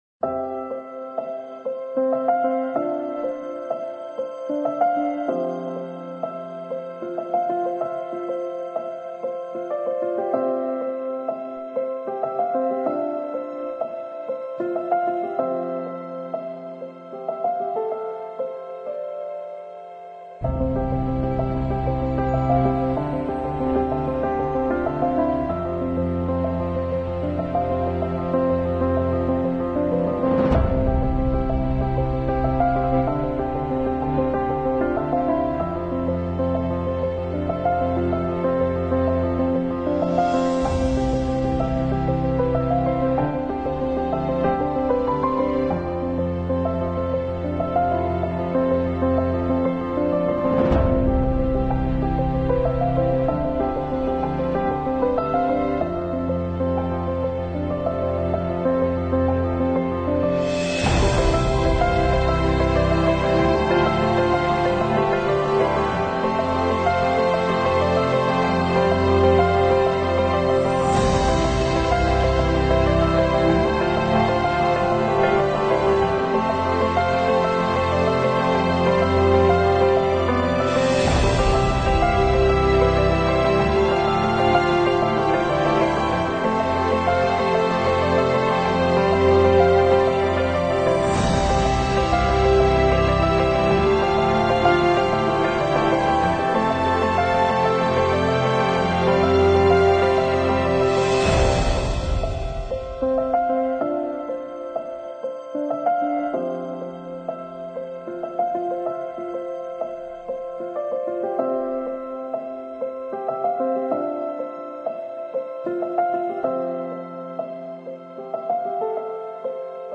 描述：由钢琴、弦乐、号角及敲击乐演奏的原创电影作品。